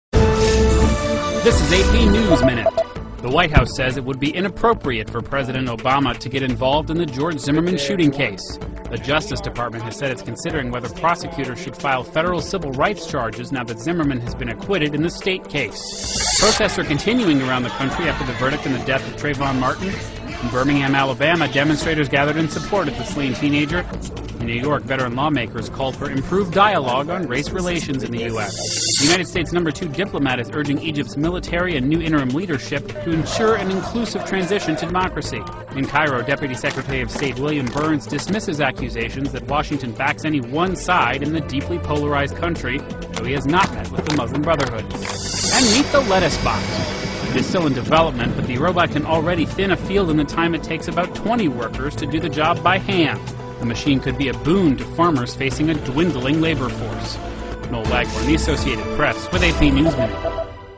在线英语听力室美联社新闻一分钟 AP 2013-07-19的听力文件下载,美联社新闻一分钟2013,英语听力,英语新闻,英语MP3 由美联社编辑的一分钟国际电视新闻，报道每天发生的重大国际事件。